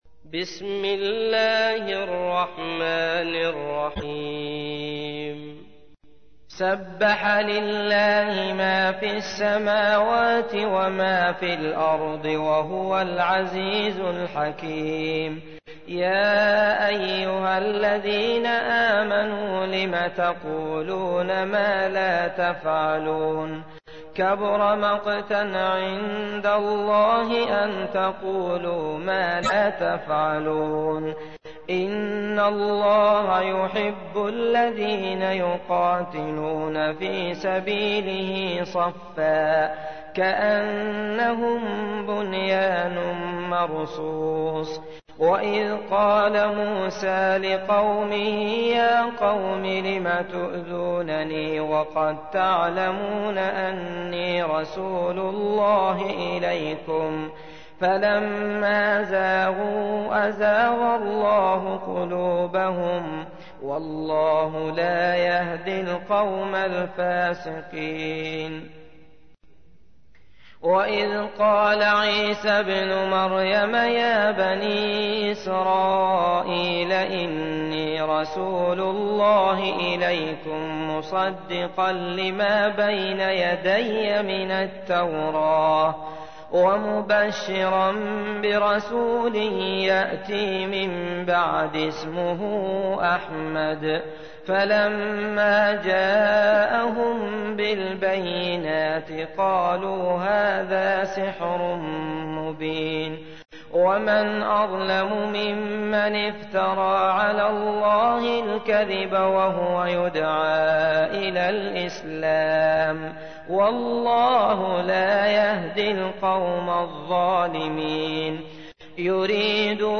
تحميل : 61. سورة الصف / القارئ عبد الله المطرود / القرآن الكريم / موقع يا حسين